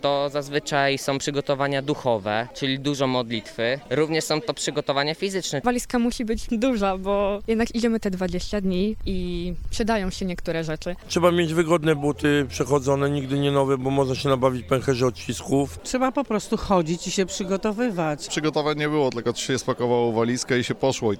Zapytaliśmy uczestników pielgrzymki, jak przygotowywali się do takiej podróży.